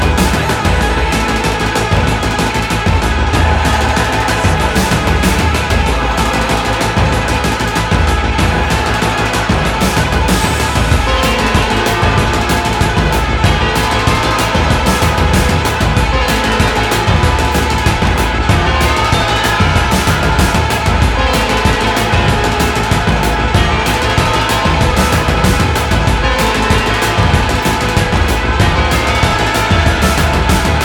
une musique pop aux contours complexes